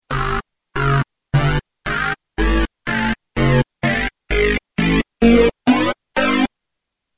intermodulation_scale.mp3